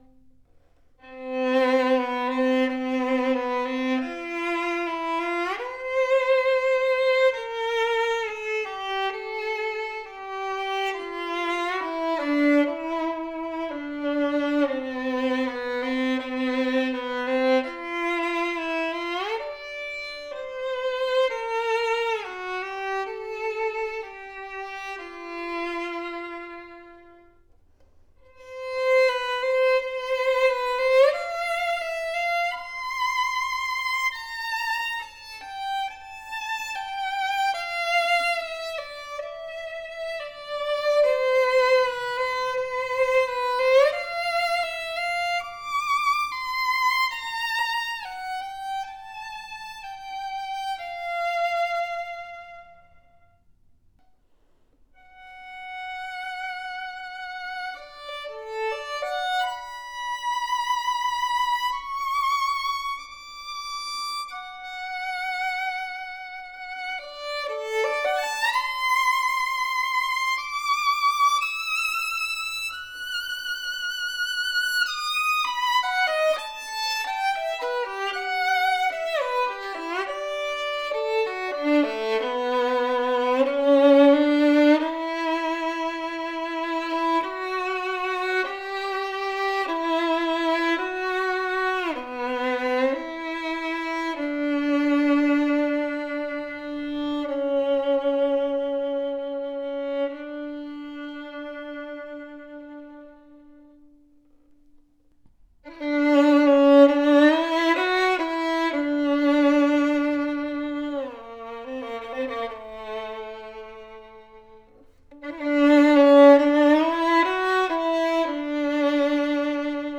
• Best academic instrument in the market guarantee, superb resonant tone and pro playability!
A projective sounding Cannone violin with deep voice, bold lower register, powerful tone with clear tone!
Full resonant G string as the typical Guarneri voice, open voice with a booming texture. Sweet even mid range. Brilliant rounded E string as the performance represents.